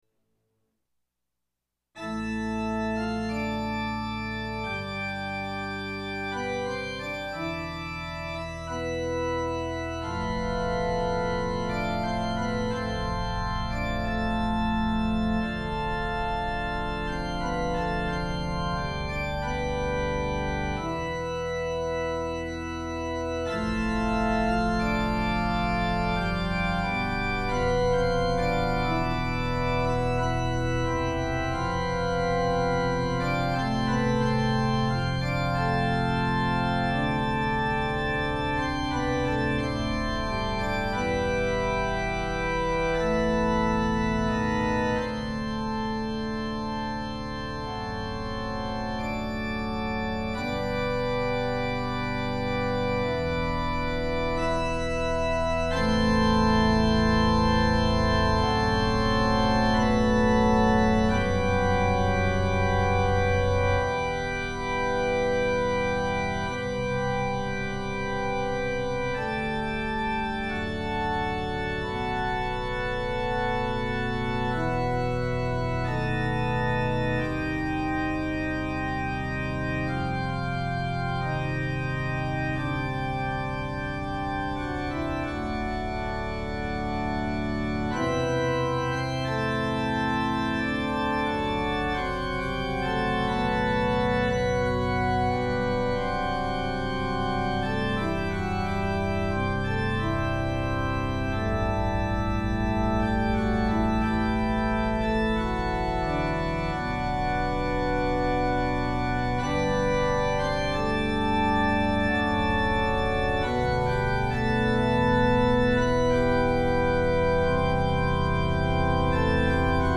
Organ Overture Sib 4 PDF (2006) Rearranged from Your Fish Tank for Organ.
MIDI-rendered.